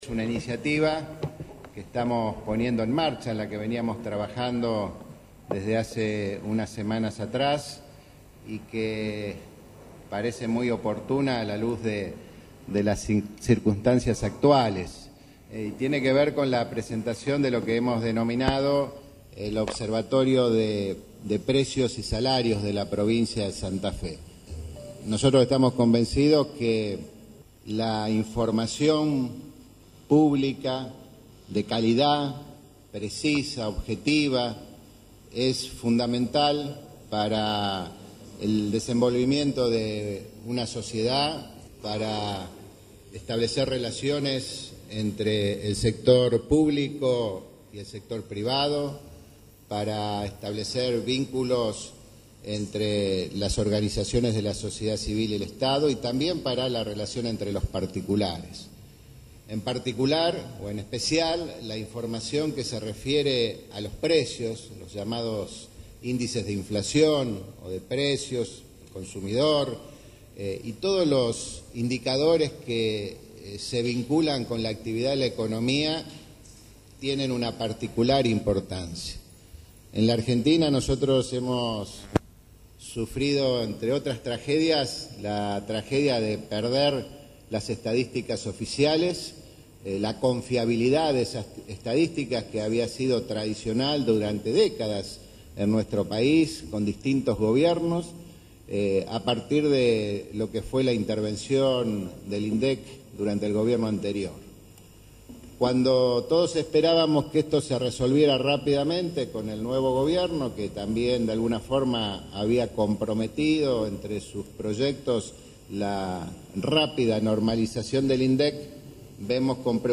El gobernador de Santa Fe, Miguel Lifschitz, junto al ministro de Economía, Gonzalo Saglione, y el secretario de Finanzas, Pablo Olivares, anunció este martes la creación del Observatorio Estadístico Provincial (OEP) para la elaboración de estadísticas e índices de precios propios de la provincia, en una conferencia de prensa realizada en Casa de Gobierno de la ciudad de Santa Fe.